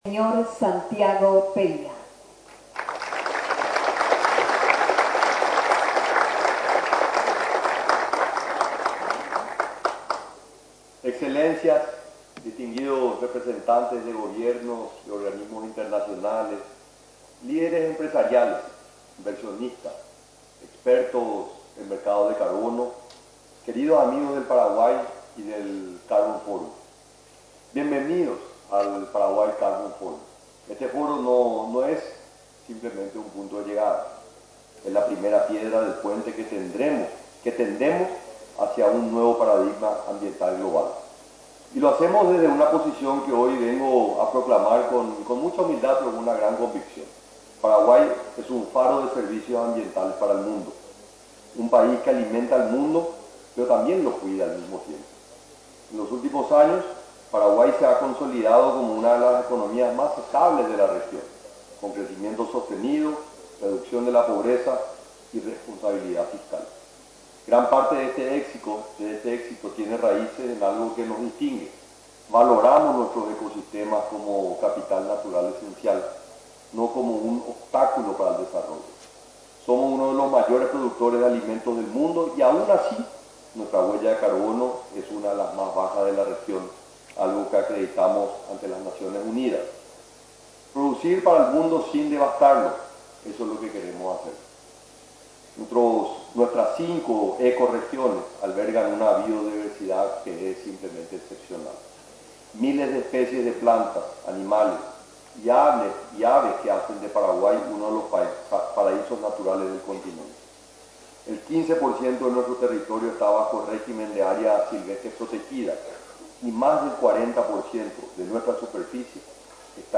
En su discurso en la apertura del Carbo Forum, que inició este miércoles, el presidente de la República, Santiago Peña, afirmó que por su abundancia de recursos naturales y baja huella de carbono, Paraguay es el aliado natural en un mundo que demanda cada vez una mayor descarbonización.